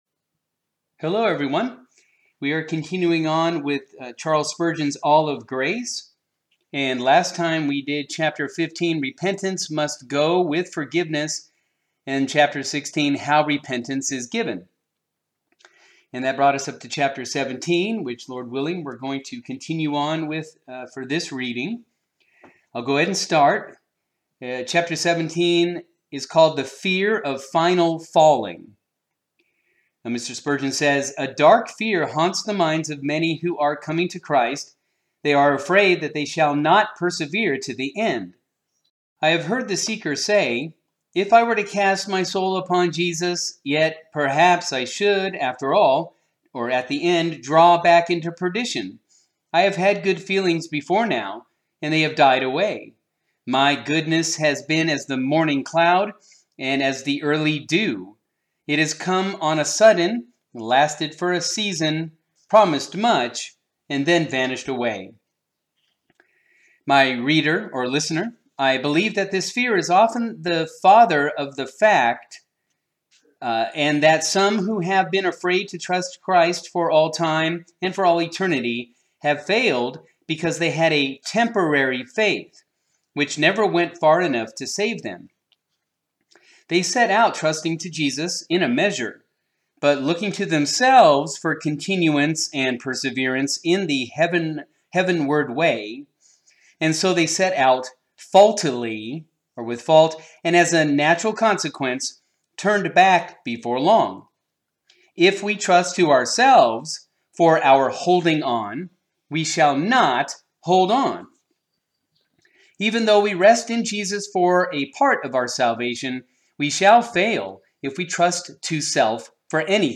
The Sifford Sojournal Audiobook: Charles Spurgeon – All of Grace